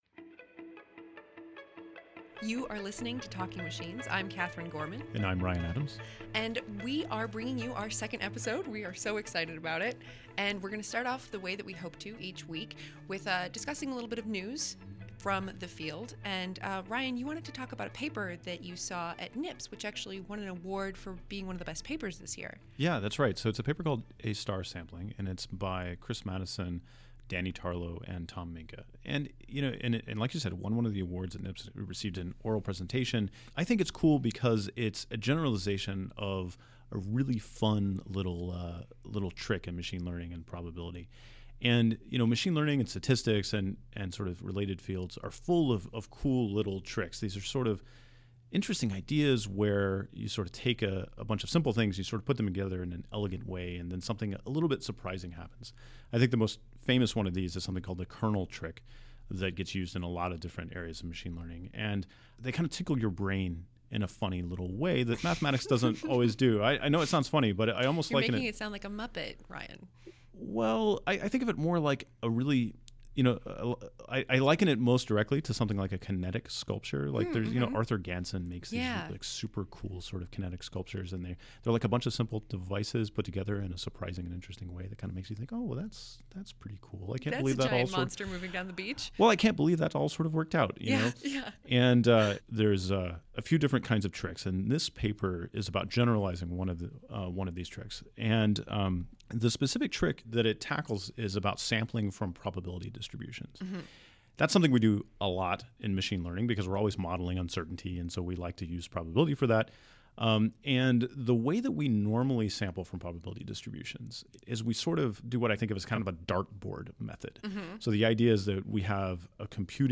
Today on Talking Machines we hear from Google researcher Ilya Sutskever about his work, how he became interested in machine learning, and why it takes a little bit of magical thinking. We take your questions, and explore where the line between human programming and computer learning actually is.